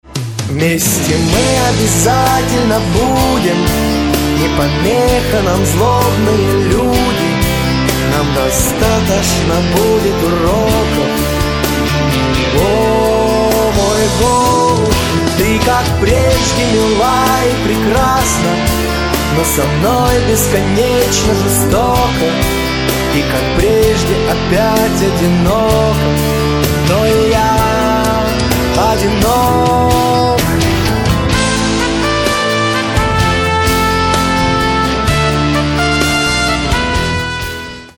• Качество: 320, Stereo
медленные
русский шансон